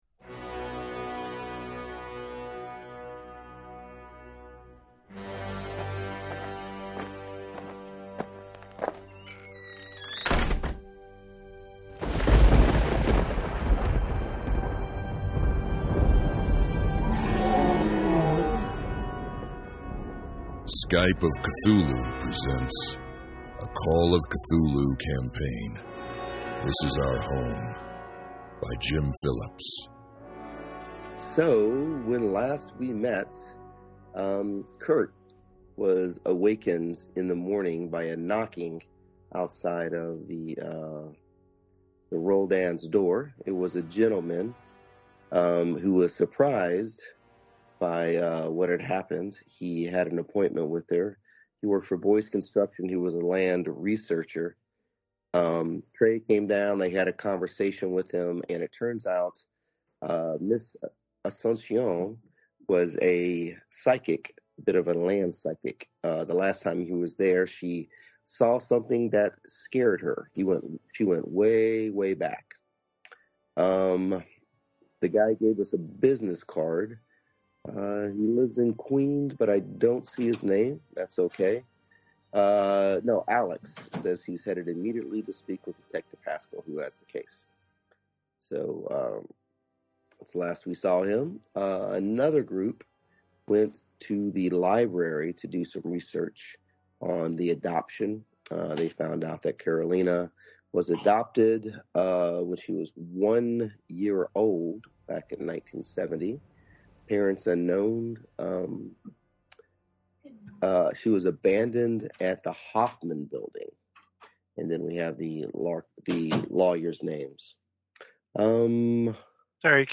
Call of Cthulhu, and perhaps other systems on occasion, played via Skype.